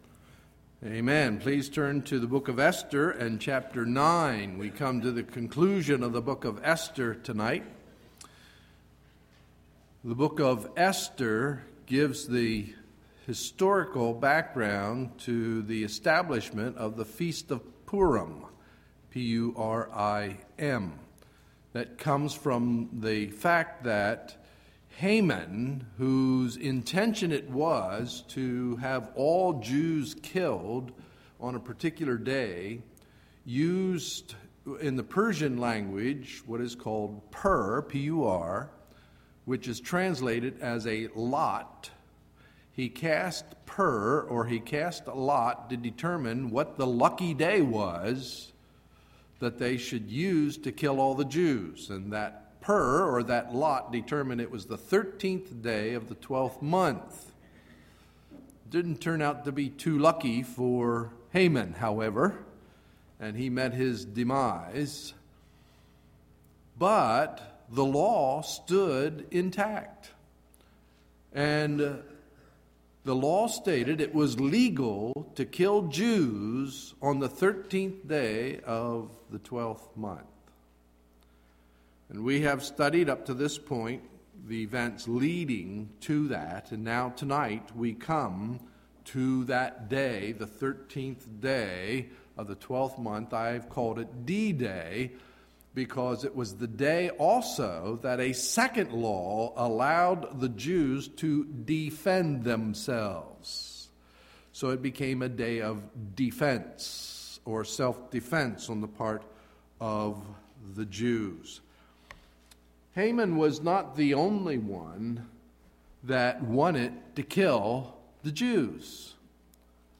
Sunday, May 8, 2011 – Evening Message